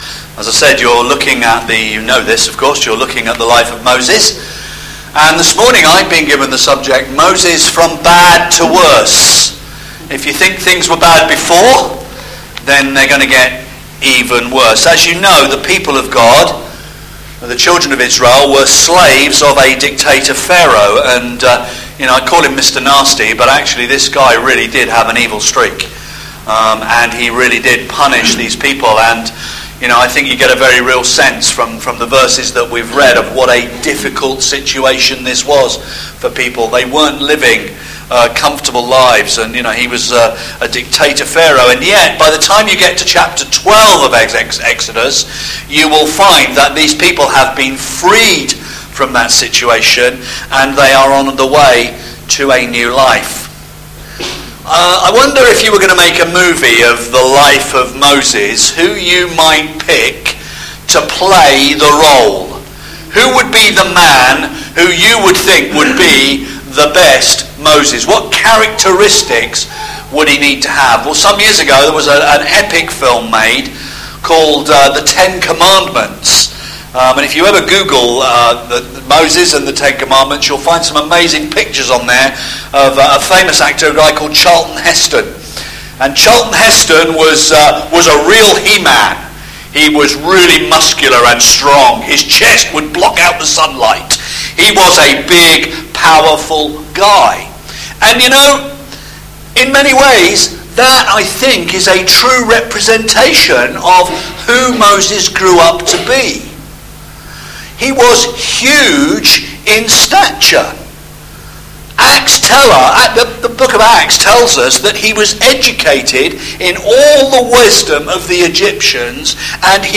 Moses’ Faith, Moses’ Choice…and Me – Hebrews chapter 11 verses 24-28– sermon